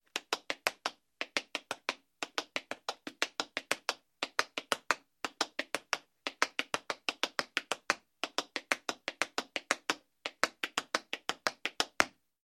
Звуки чечетки
На этой странице собраны яркие звуки чечетки в разных темпах и вариациях.
Стук каблуков в ритме танца